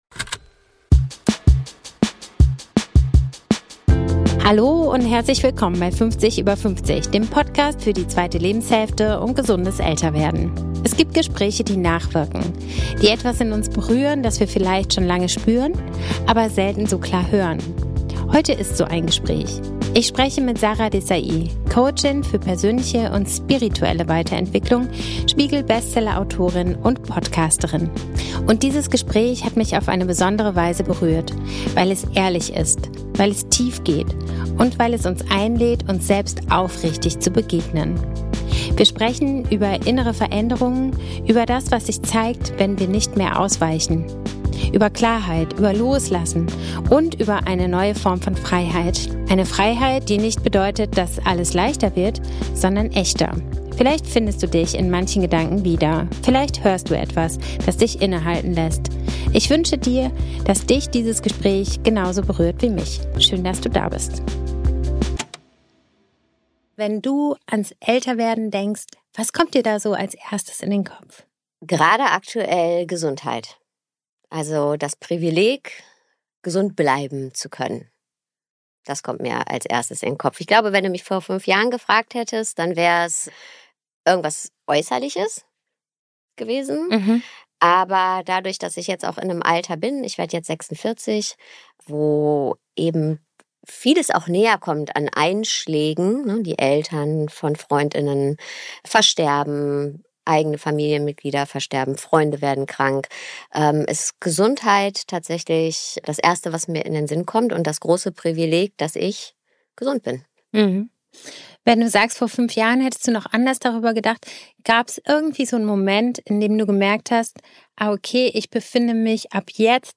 Heute ist so ein Gespräch.